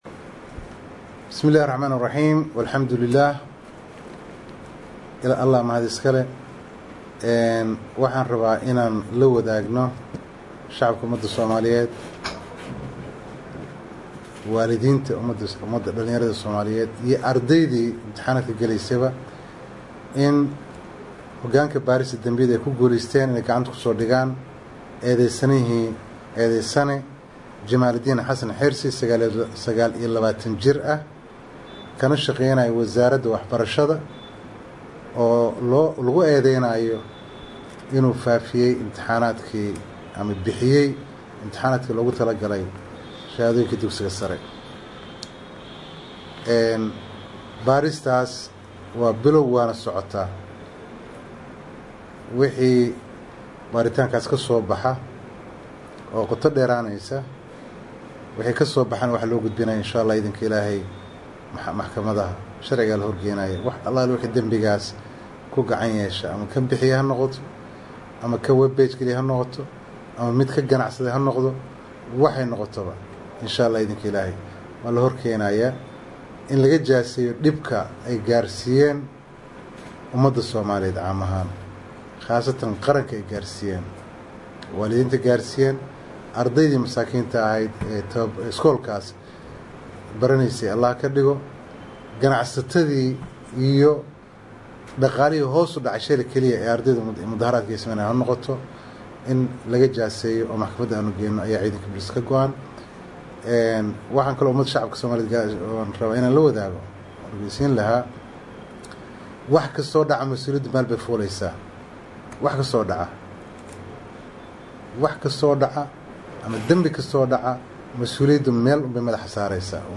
Taliyaha ciidanka booliska Soomaaliyeed Jeneraal Bashiir Cabdi Maxamed oo shir saxaafadeed qabtay maanta ayaa sheegay in hogaanka dambi baarista ay
Taliyaha-Booliska-Soomaaliya-Jeneraal-Bashiir-Cabdi-Maxamed-1.mp3